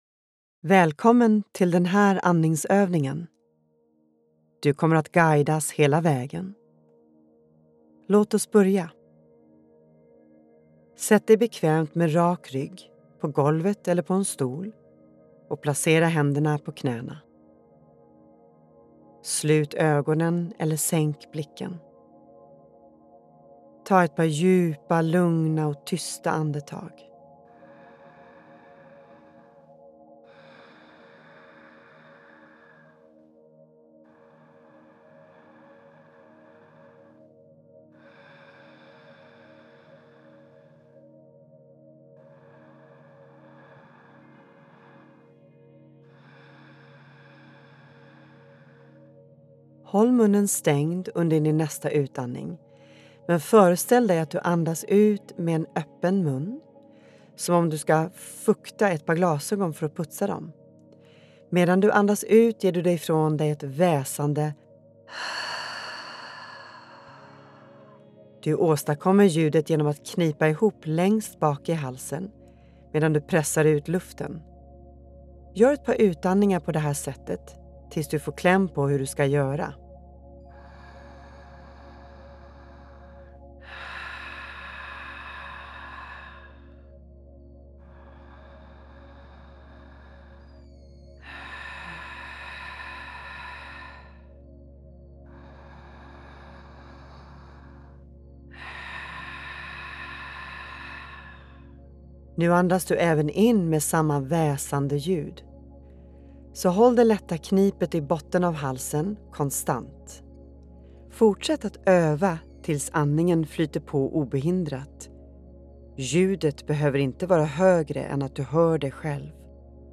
Den segrande andningen – andningsövning med ljudguide
• Du börjar med att få en introduktion till andningsövningen.
• Därefter får du 3 min för att fortsätta övningen i ditt eget tempo.